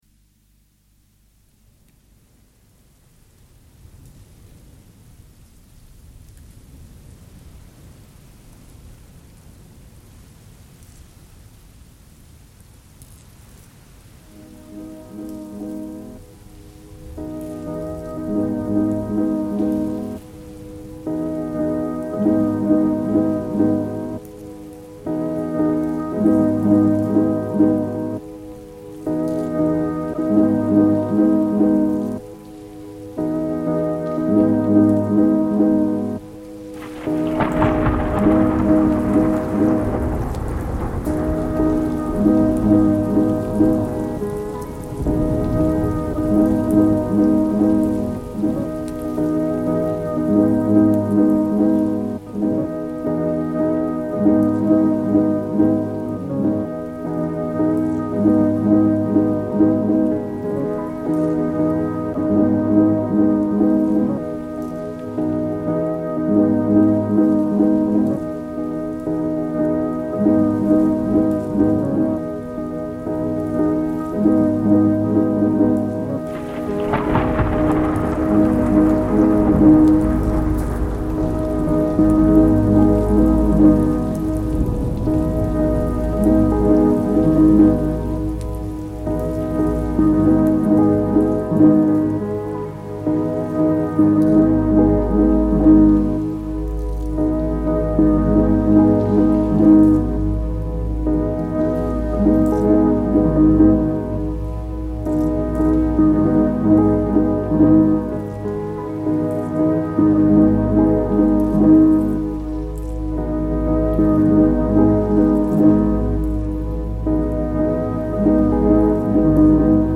Lockdown thunderstorm in Oxford reimagined